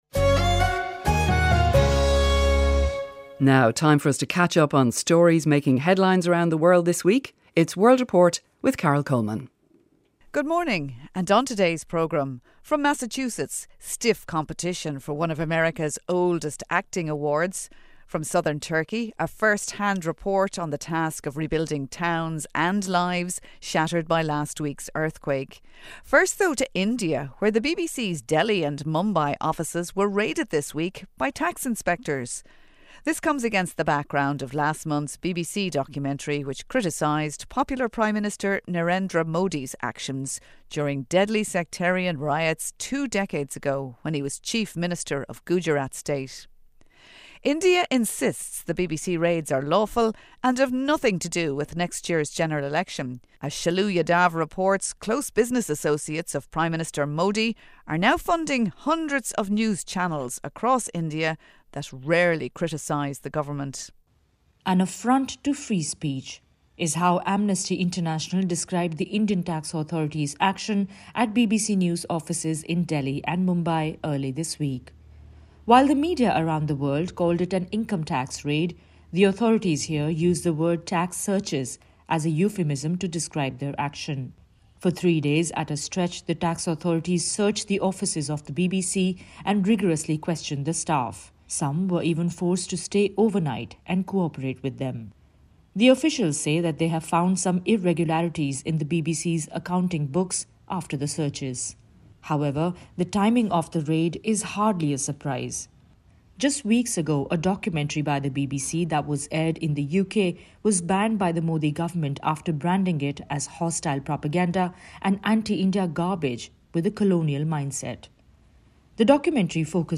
8:35am Sports News - 18.07.2023